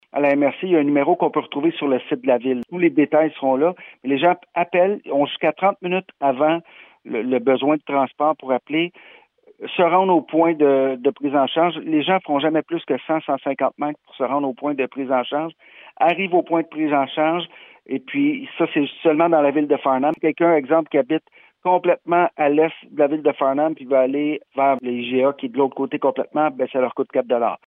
Comment fonctionnera ce service ? Patrick Melchior, maire de Farnham.